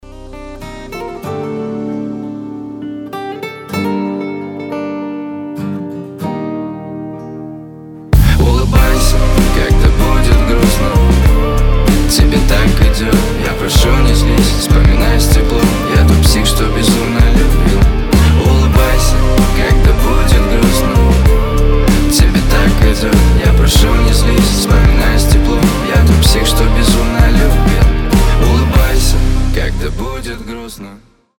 • Качество: 320, Stereo
гитара
мужской голос
лирика